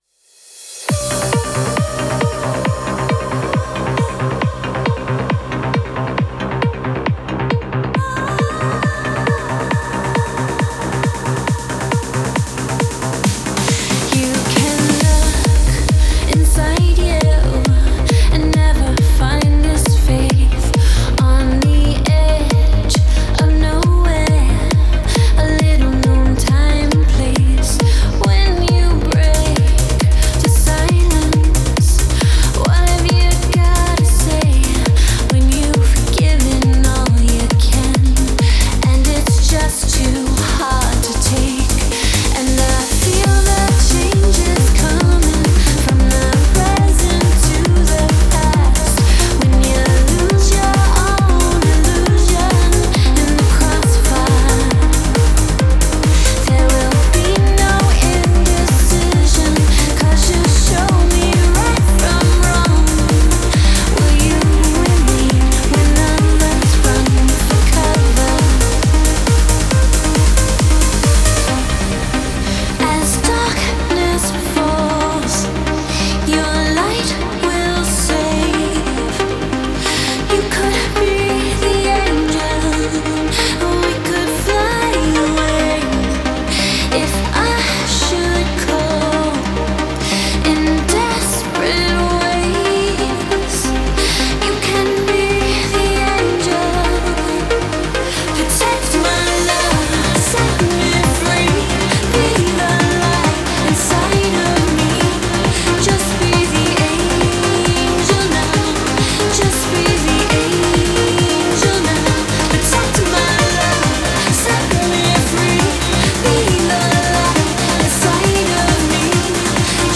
в жанре транса